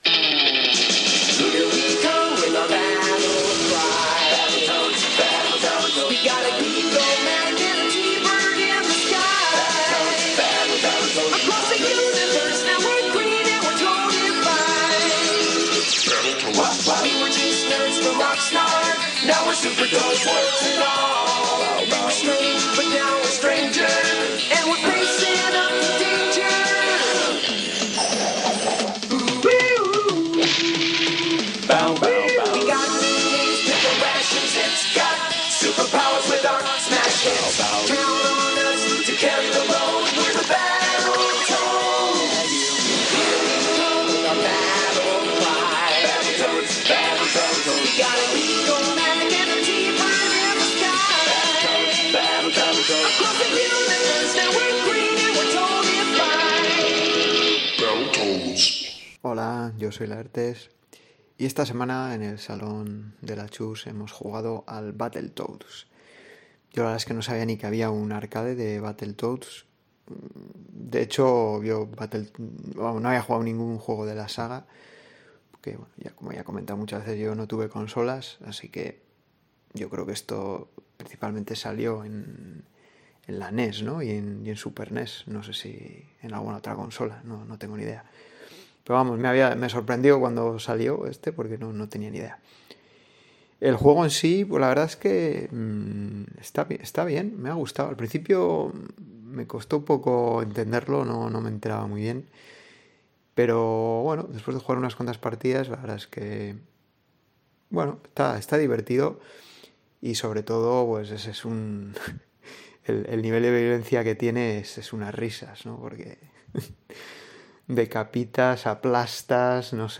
Como siempre, la duración de este episodio depende de vuestros audios… y esta semana han llegado 5 aportaciones, así que la cosa no ha estado nada mal.